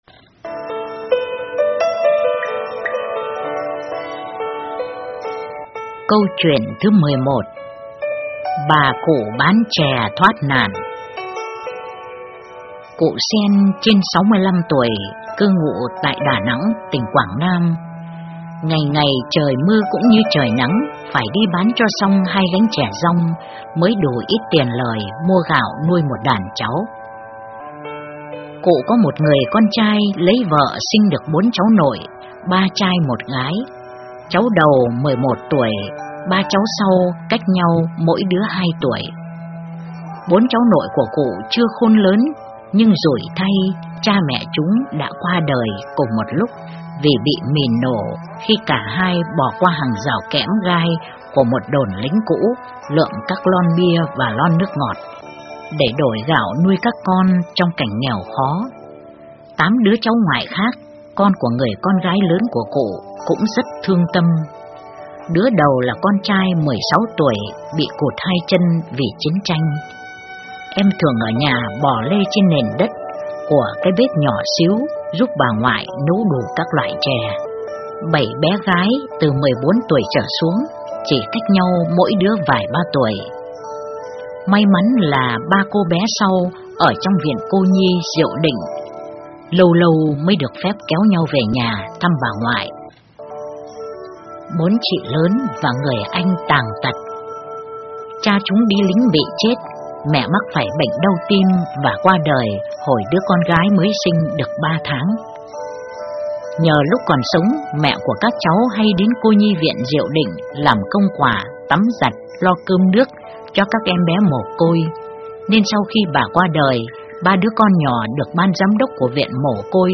Truyện Đọc Những Mẫu Chuyện Linh Ứng Quan Thế Âm (giọng khác)